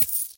coins2.ogg